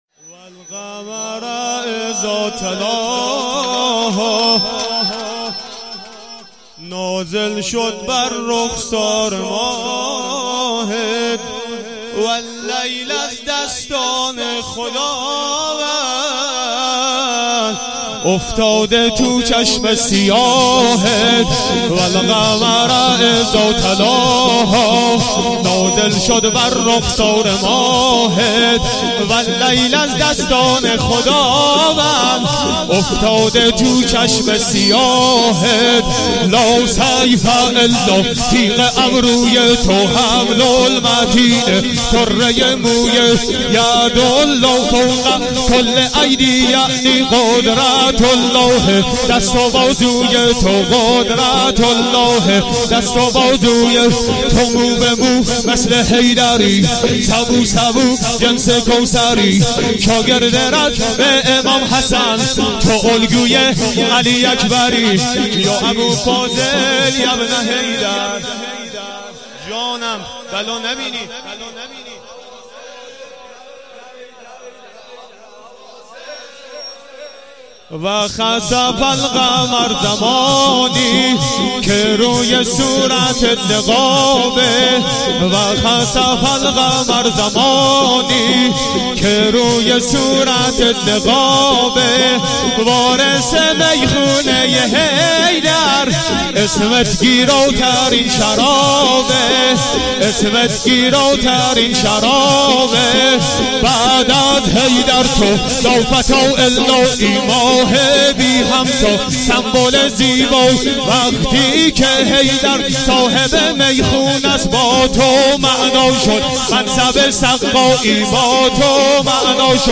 مراسم فاطمیه دوم 96.11.27